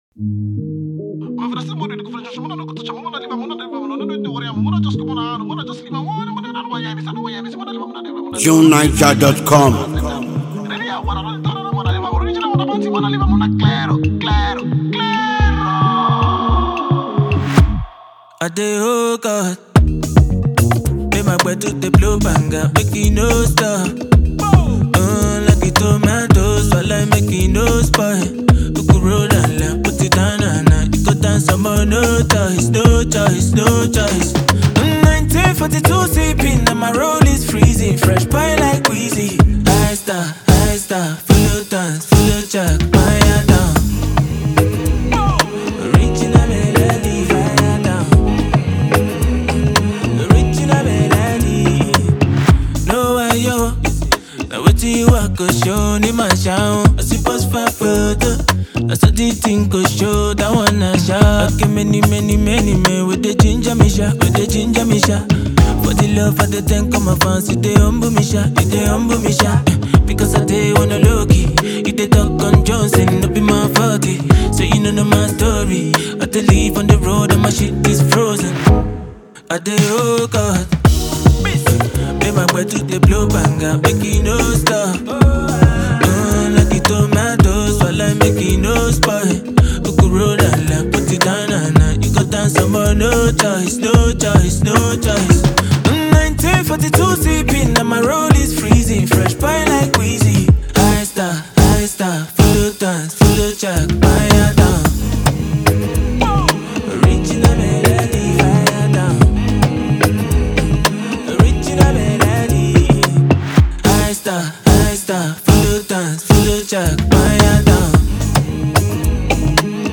multi-talented Nigerian singer and music producer